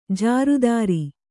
♪ jāru dāri